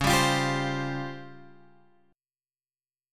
C# Major 7th Flat 5th